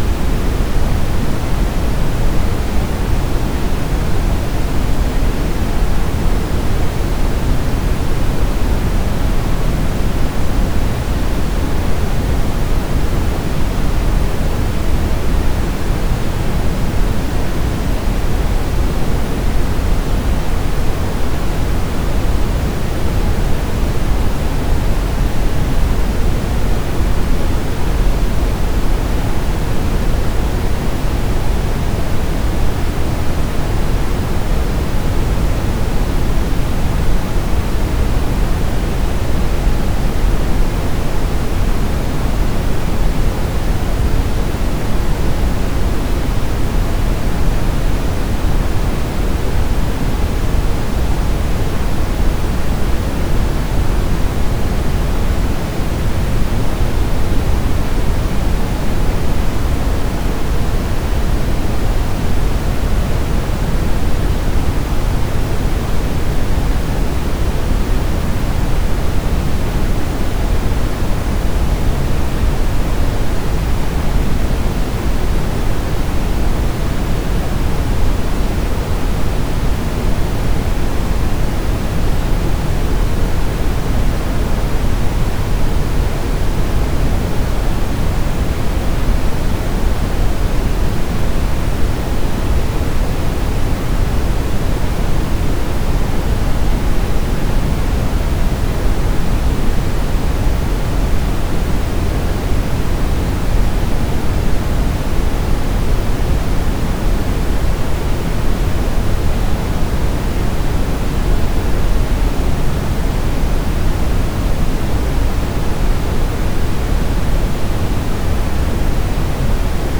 an hour of brown noise being silenced.ogg